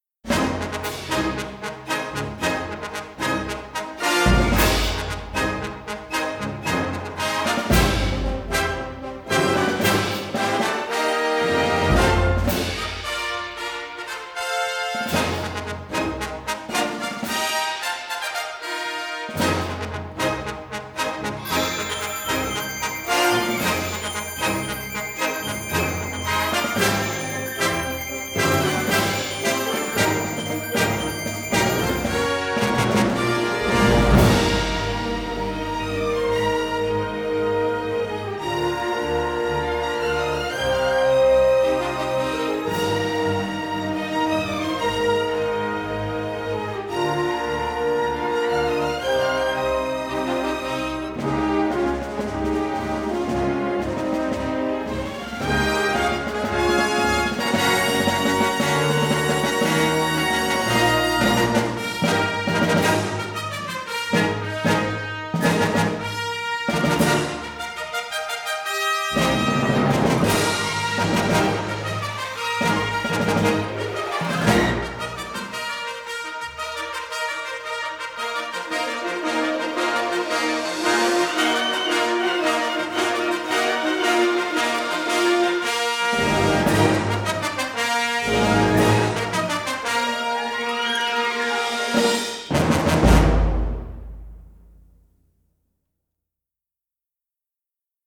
Unusually crisp, punchy recording